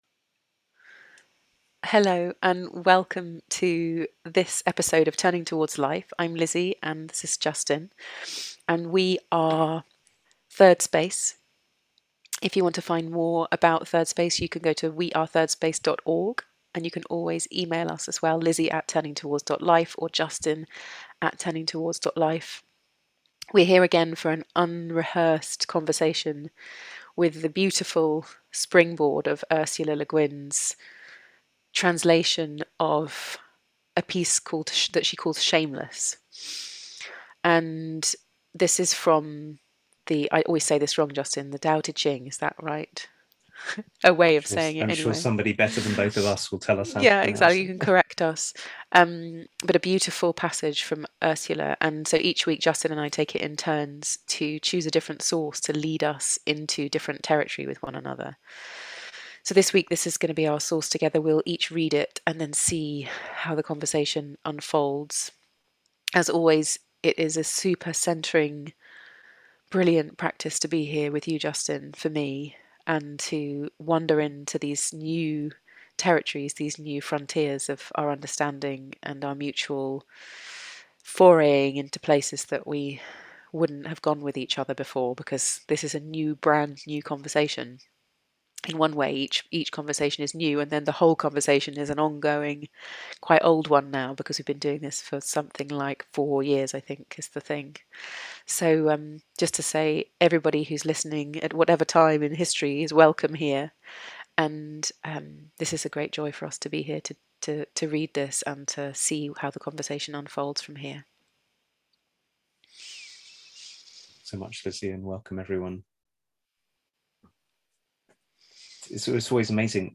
This week's Turning Towards Life is a conversation about how we might cultivate the shameless leadership that's called for right now.